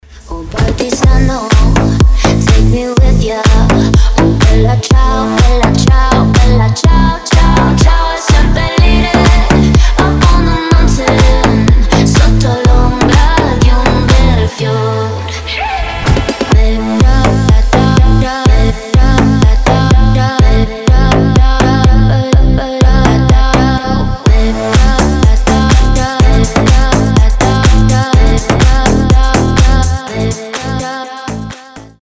• Качество: 320, Stereo
retromix
басы
ремиксы
slap house